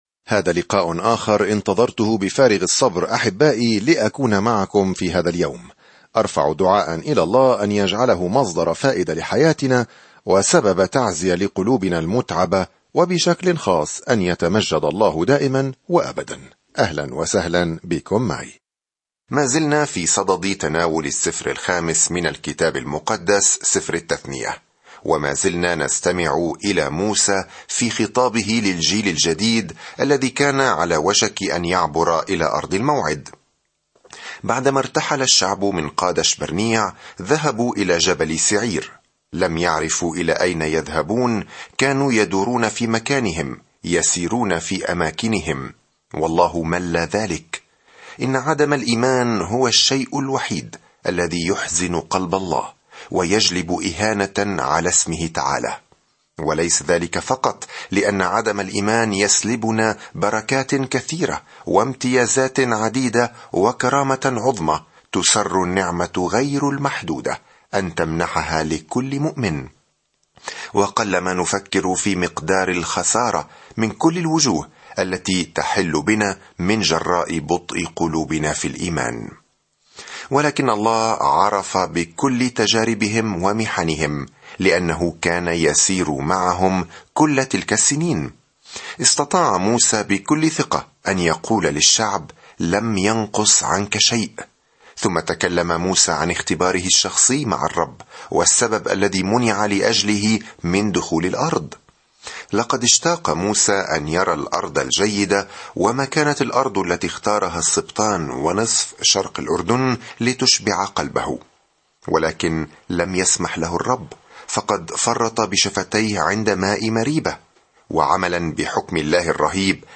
الكلمة اَلتَّثْنِيَة 3:4-49 يوم 2 ابدأ هذه الخطة يوم 4 عن هذه الخطة يلخص سفر التثنية شريعة الله الصالحة ويعلمنا أن الطاعة هي استجابتنا لمحبته. سافر يوميًا عبر سفر التثنية وأنت تستمع إلى الدراسة الصوتية وتقرأ آيات مختارة من كلمة الله.